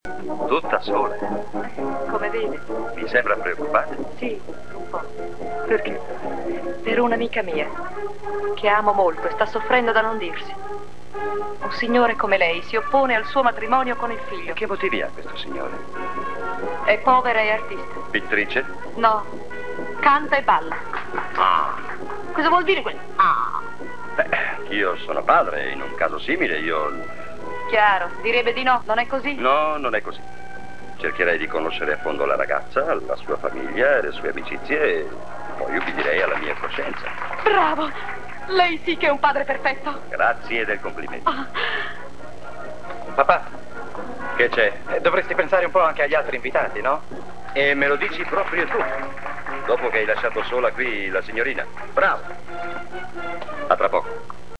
voce di Otello Toso nel film "Pane, amore e Andalusia", in cui doppia José Nieto.